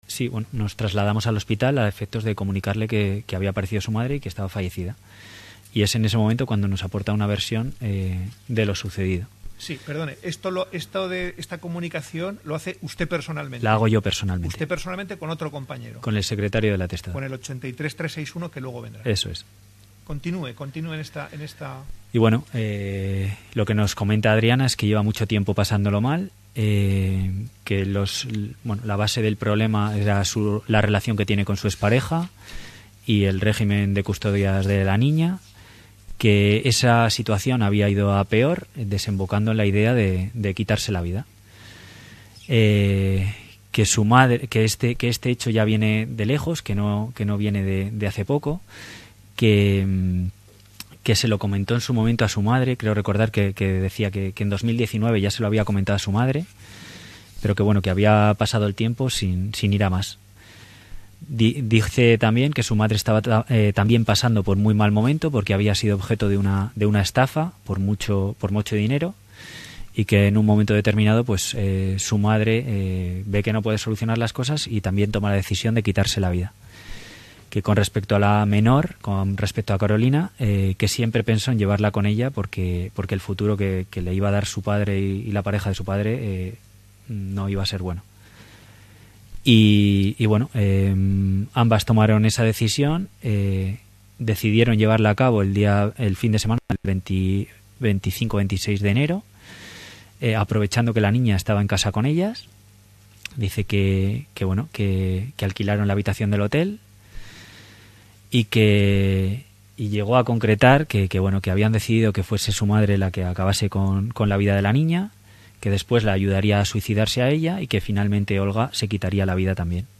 JUICIO-INVESTIGADOR-HECHOS-1_.mp3